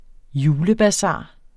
Udtale [ ˈjuːlə- ]